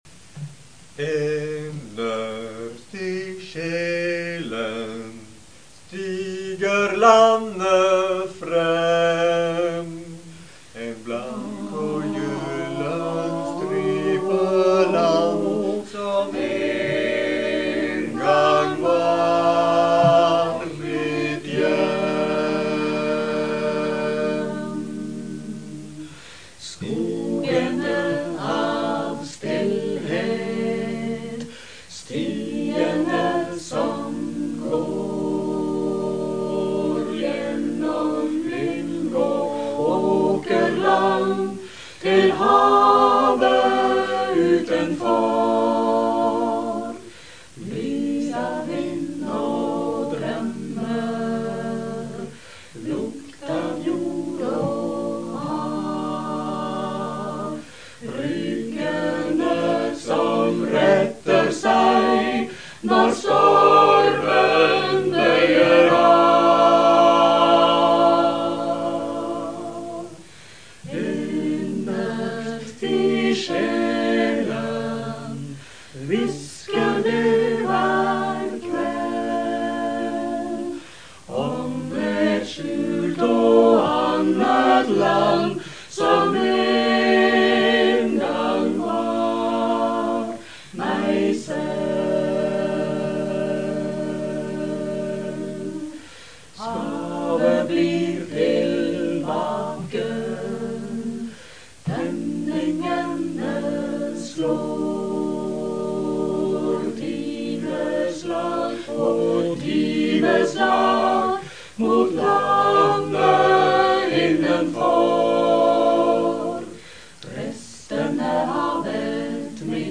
Minneseremoni 8. mai på Bygdøynes. Klikk for å høre Hummer & Canari synge Innerst i sjelen (opptak fra øvelse).
Sanggruppen Hummer & Canari, som bloggeren var med i til den ble nedlagt, var i mange år et fast innslag på programmet under samværet etter kransenedleggelsen 8. mai ved Krigsseilermonumentet på Bygdøynes.